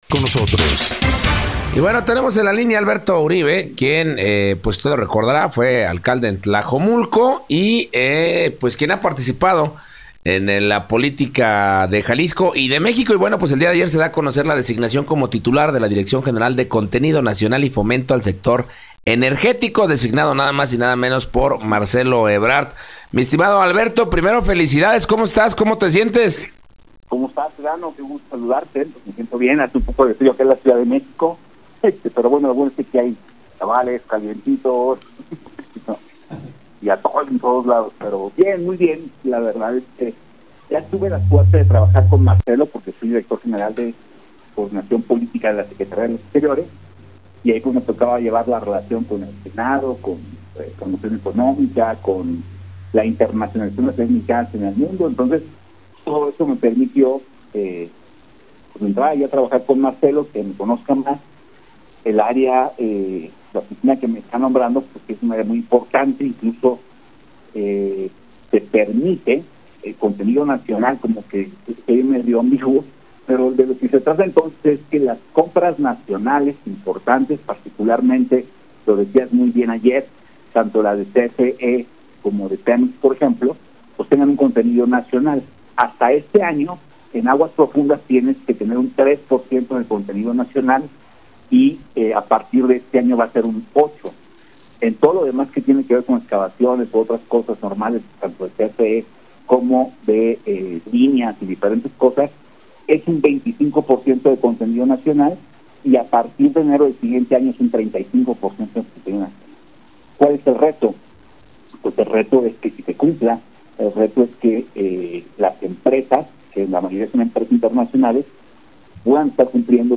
En Entrevista para la primera emisión del noticiero En Punto, Alberto Uribe nos platicó las acciones que realizará en su nuevo cargo en el Gobierno Federal y como va a relacionarse con las autoridades jaliscienses.
entrevista-a-alberto-uribe-1.wav